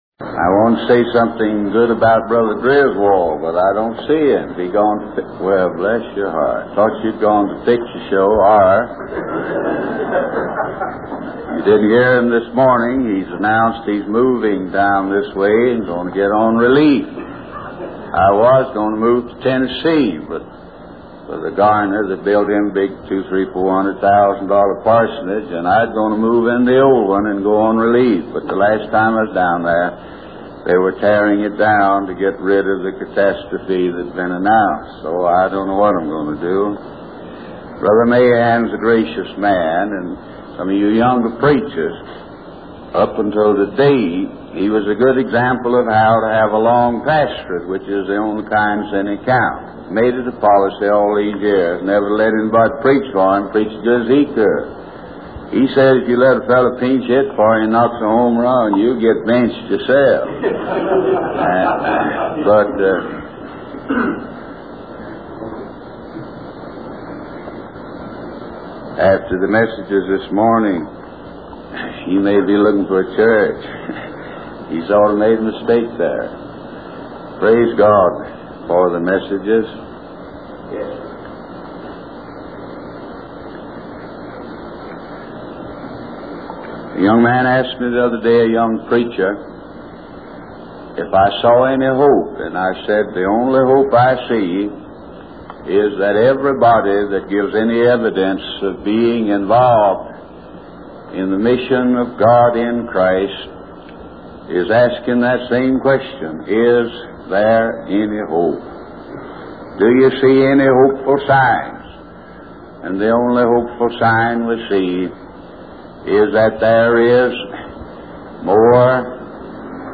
We Will Not Have This Man Rule | SermonAudio Broadcaster is Live View the Live Stream Share this sermon Disabled by adblocker Copy URL Copied!